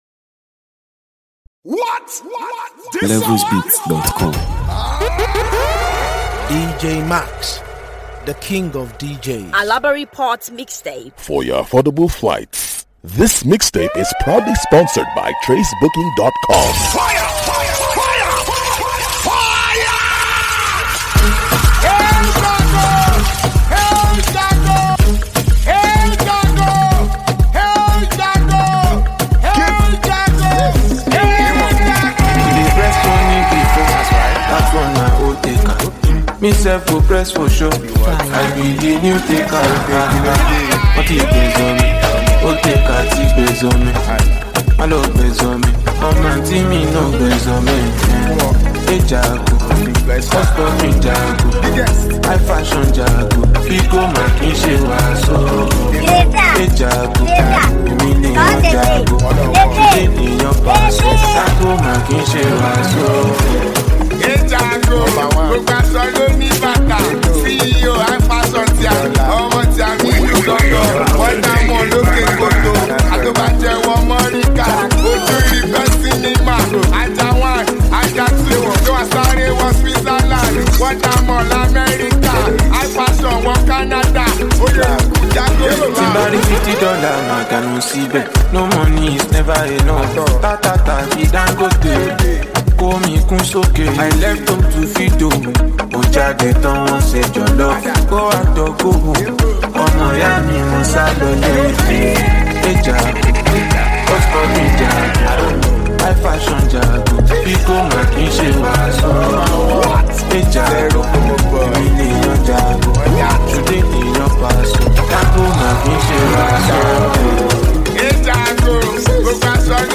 proudly presents the exciting new mixtape
a highly talented Nigerian disc jockey and mixmaker.